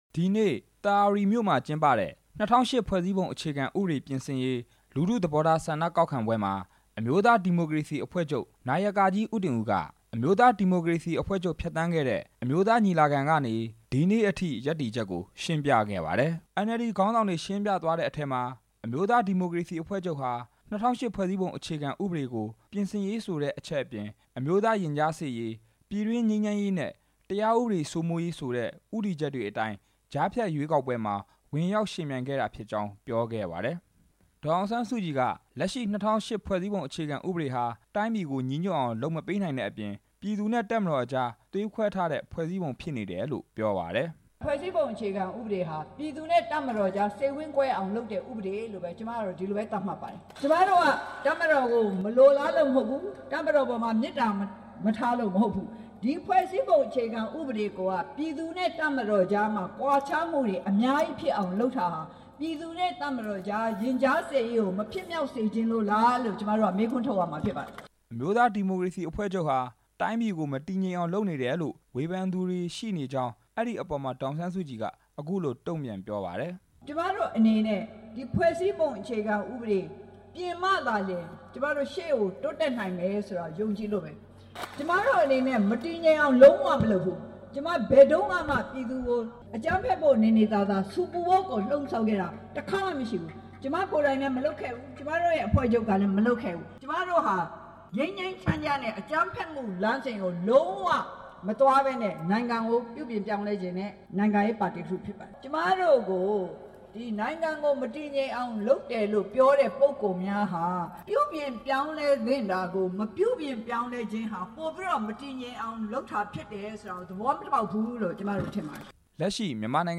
ပဲခူးတိုင်းဒေသကြီး သာယာဝတီမြို့မှာ ဒီနေ့ ပြုလုပ်တဲ့ ၂၀၀၈ ဖွဲ့စည်းပုံကို ပြင်ဆင်မလား အသစ်ပြန်လည် ရေး ဆွဲမလား လူထုဆန္ဒသဘောထား ကောက်ခံပွဲ မှာ ဒေါ်အောင်ဆန်းစုကြည်က အခုလို ပြောလိုက်တာပါ။